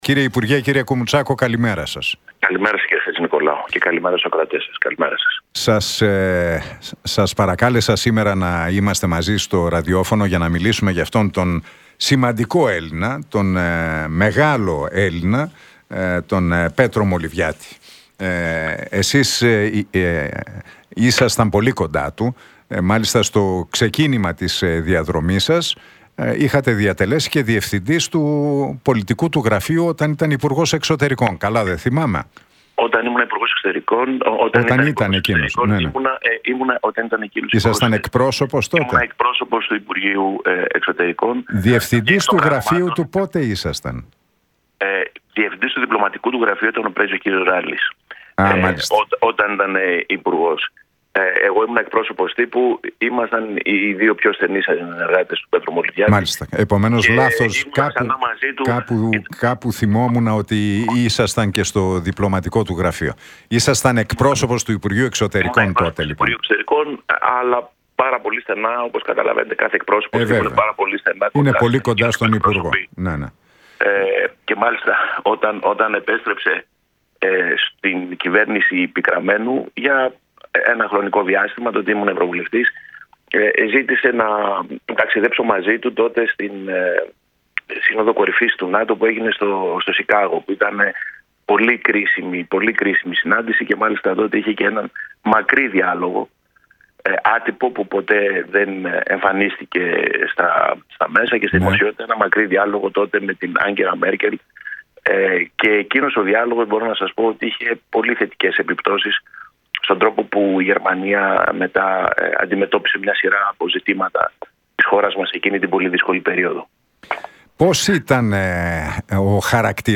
Για τον πρώην υπουργό Εξωτερικών και διπλωμάτη, Πέτρο Μολυβιάτη που πέθανε στις 4 Μαΐου αλλά και την παρακαταθήκη του μίλησε ο εκπρόσωπος της Ελλάδας στην UNESCO, Γιώργος Κουμουτσάκος στον Νίκο Χατζηνικολάου από την συχνότητα του Realfm 97,8.